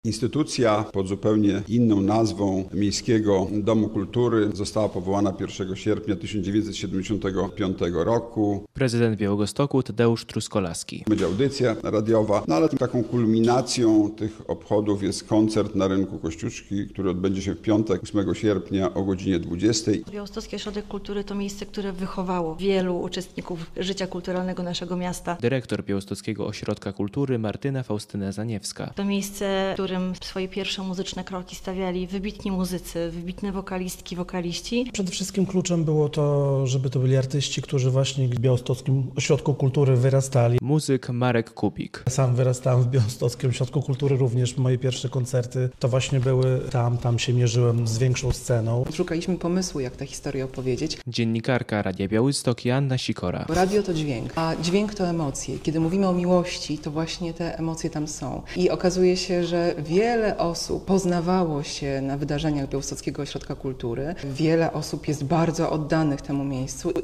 Białostocki Ośrodek Kultury świętuje 50-lecie - relacja
Podczas konferencji prasowej prezydent Białegostoku Tadeusz Truskolaski zapowiedział, jakie atrakcje przygotowano na 50-lecie BOK.